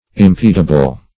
Search Result for " impedible" : The Collaborative International Dictionary of English v.0.48: Impedible \Im*ped"i*ble\, a. Capable of being impeded or hindered.